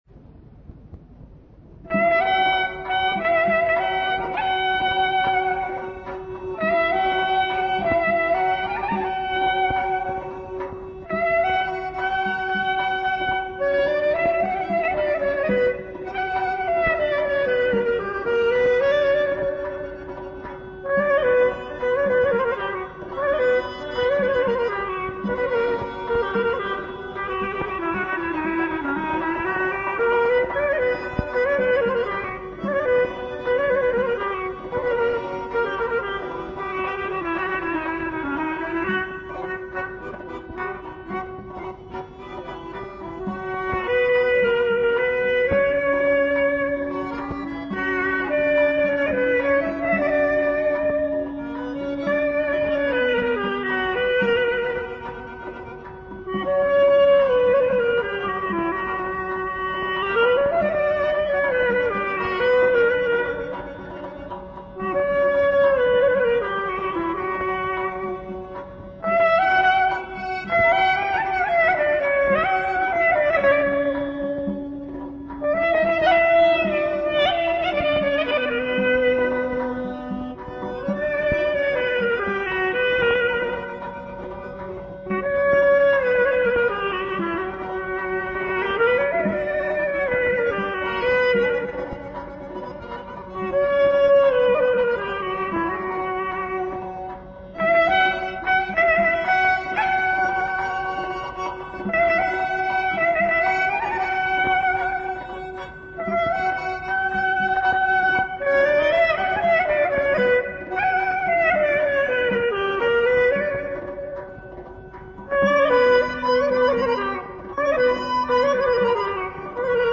AZƏRBAYCAN XALQ RƏQS MUSİQİSİ
nağara
qarmon
klarnet
balaban.- Qramval №18036.- CD №631.